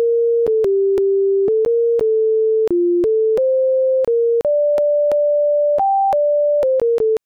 Hair thickness is proportional to the number of different patterns in a tune, and these tunes have, relatively speaking, a small number of different patterns, largely because they lack tied notes and large intervals, features common to many folk tunes.